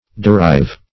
Derive \De*rive"\, v. i.